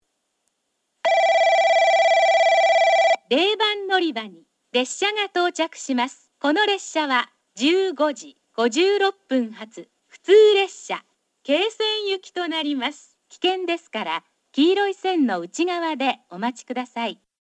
0番のりば接近放送（普通　桂川行き）
放送はJACROS簡易詳細型です。
スピーカーは0，1がカンノボックス型、2，3がカンノや円型ワイドホーン、TOAラッパ型です。